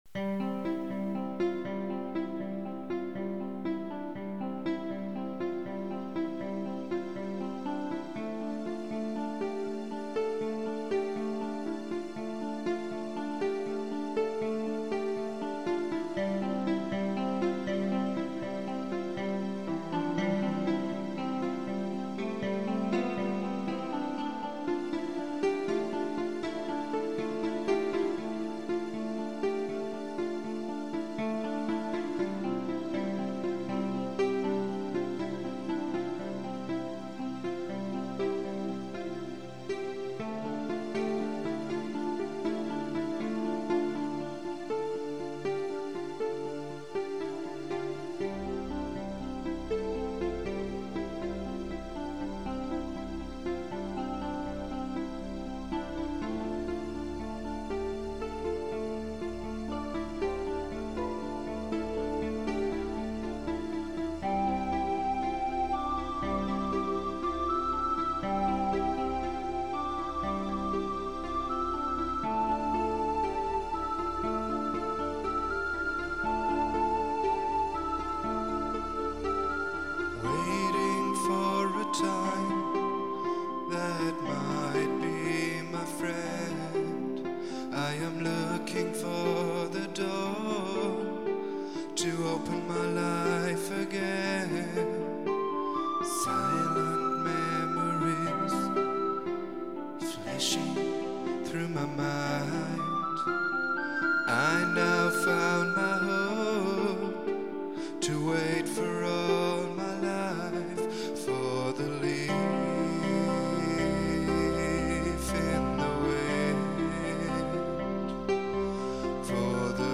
Eine weitere Bombast-Ballade, inspiriert seinerzeit von "Forrest Gump".